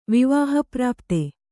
♪ vivāha prāpte